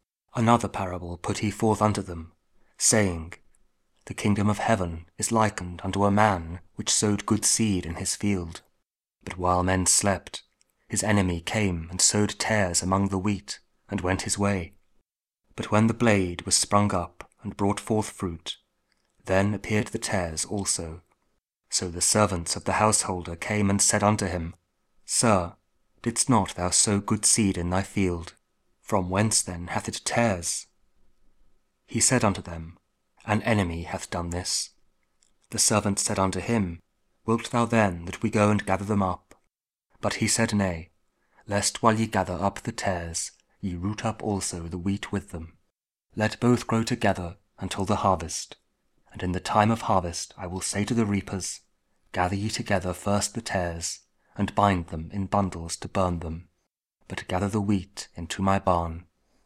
Matthew 13: 24-30 – Week 16 Ordinary Time, Saturday (King James Audio Bible KJV, King James Version Spoken Word)